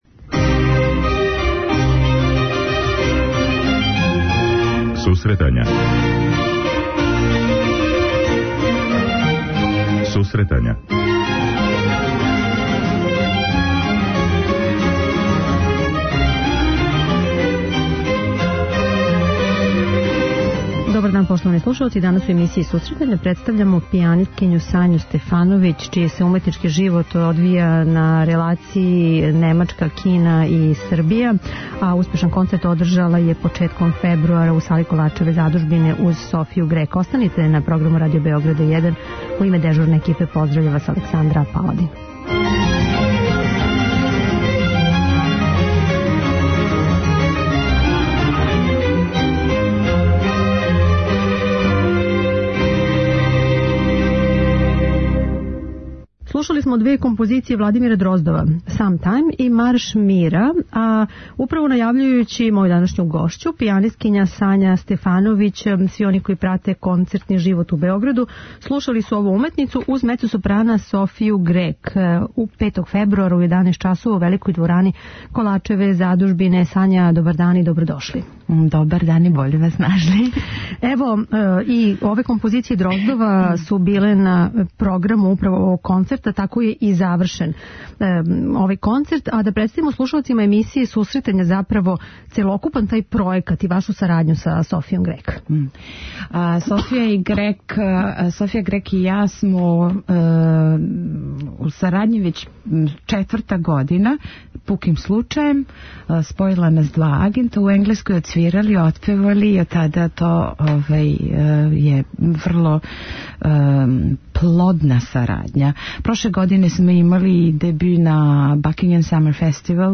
преузми : 10.61 MB Сусретања Autor: Музичка редакција Емисија за оне који воле уметничку музику.